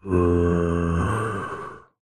Sound / Minecraft / mob / zombie3